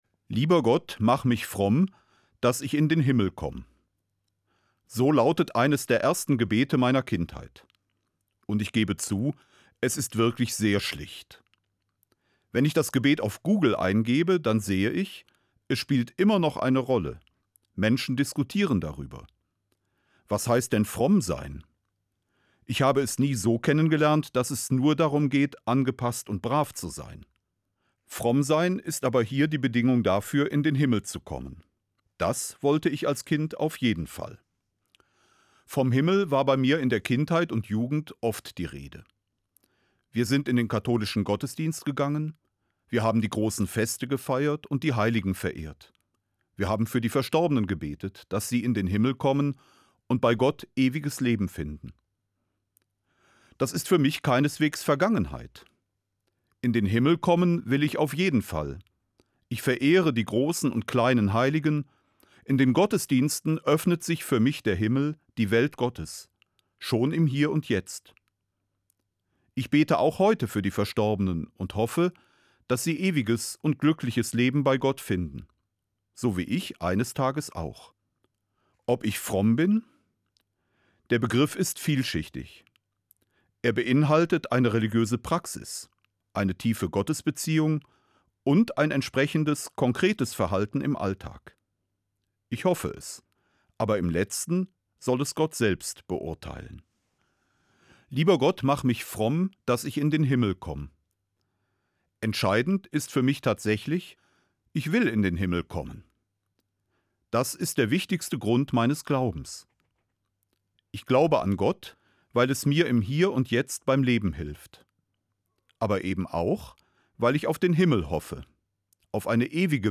Eine Sendung von Peter Kohlgraf, Bischof von Mainz